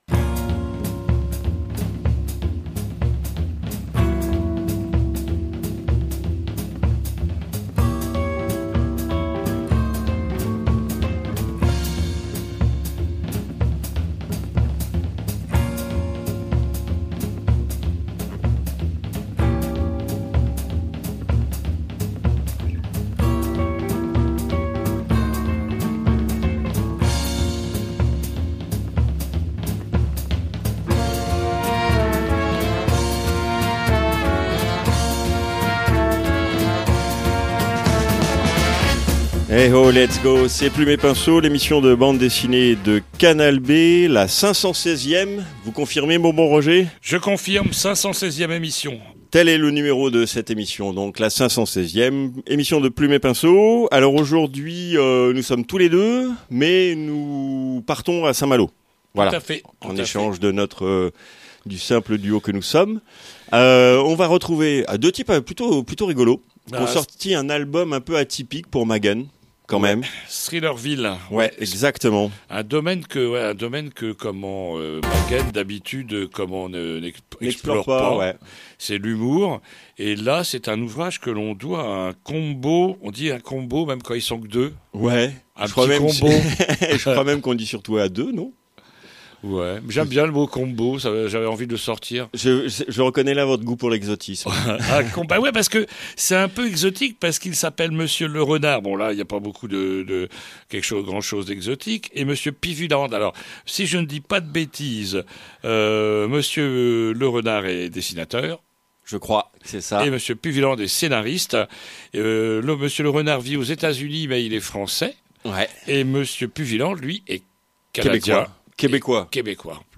I - INTERVIEW Thrillerville est une comédie horrifique conçue par Alex Puvilland au dessin et Lerenard au scénario parue aux éditions MAGHEN